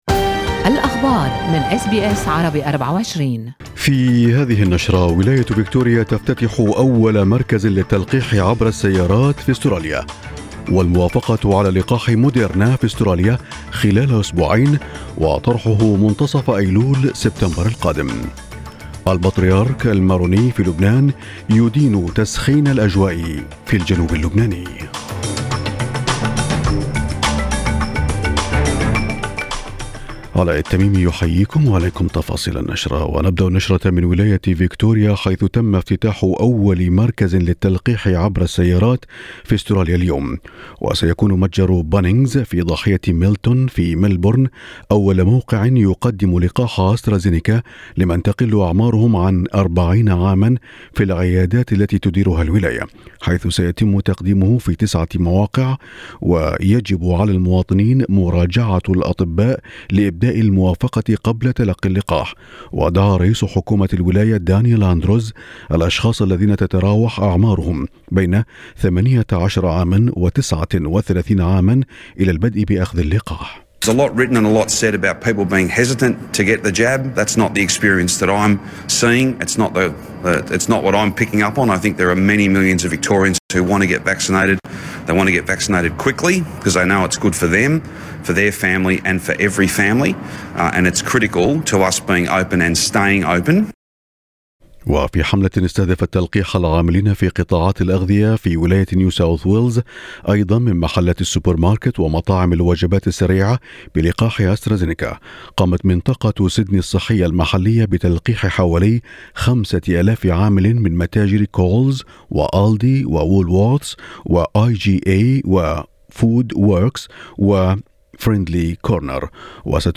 نشرة أخبار الصباح 9/8/2021